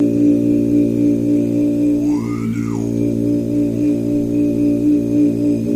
Human Voice Low "Whoooa